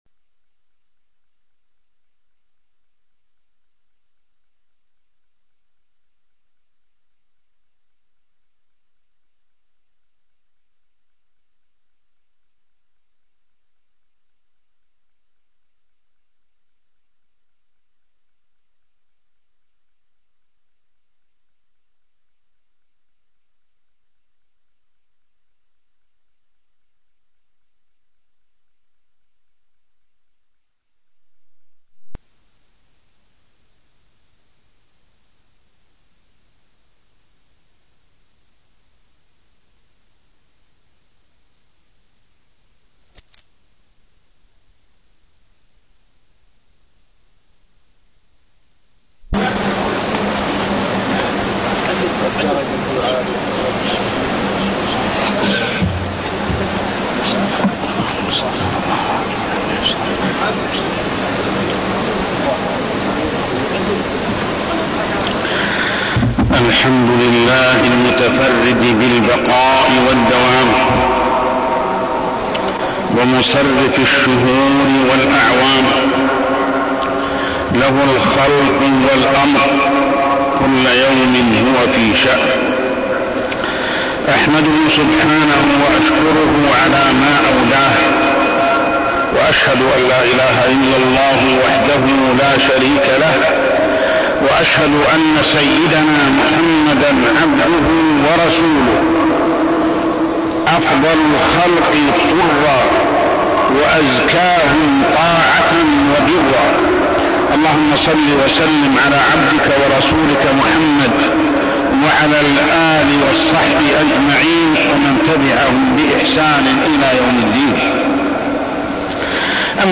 تاريخ النشر ٥ محرم ١٤١٤ هـ المكان: المسجد الحرام الشيخ: محمد بن عبد الله السبيل محمد بن عبد الله السبيل صبر النبي صلى الله عليه وسلم